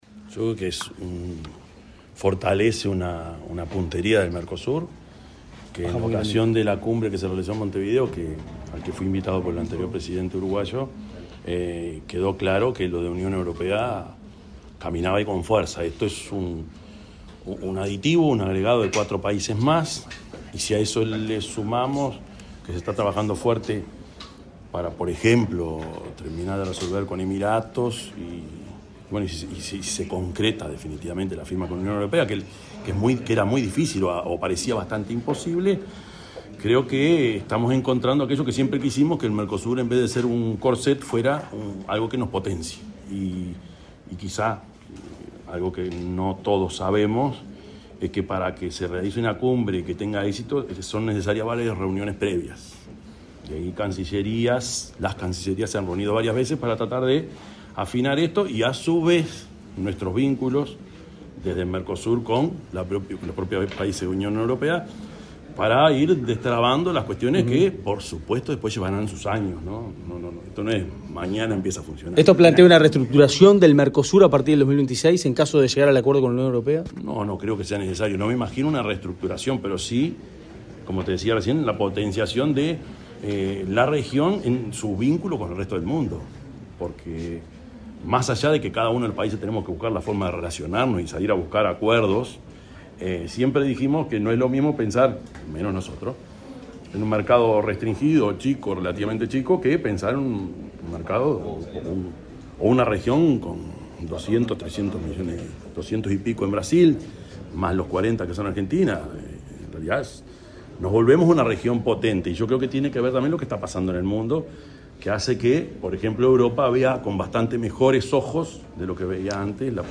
Declaraciones del presidente de la República, Yamandú Orsi
Declaraciones del presidente de la República, Yamandú Orsi 02/07/2025 Compartir Facebook X Copiar enlace WhatsApp LinkedIn Luego de recibir el premio de doctor “honoris causa”, otorgado por la Universidad del Congreso, de Mendoza, el presidente de la República, Yamandú Orsi, dialogó con los medios de comunicación en Buenos Aires.